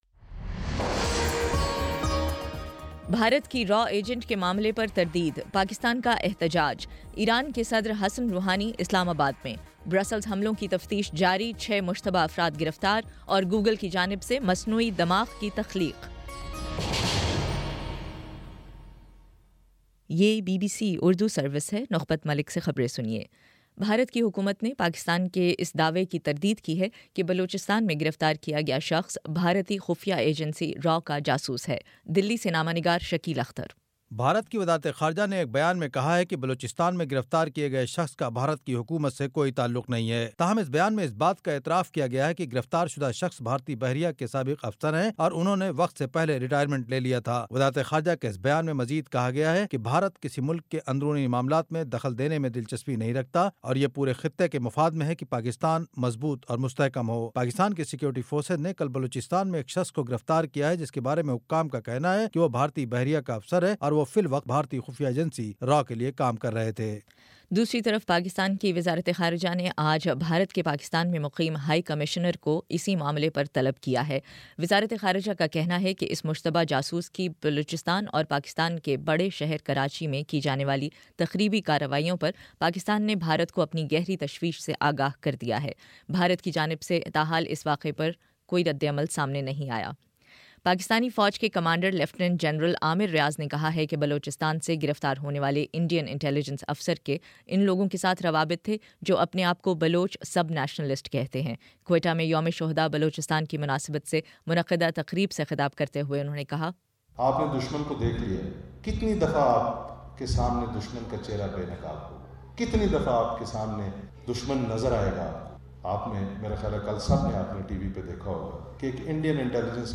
مارچ 25 : شام چھ بجے کا نیوز بُلیٹن